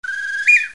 Cinguettio uccello
Uccello tropicale cinguettio con due timbri.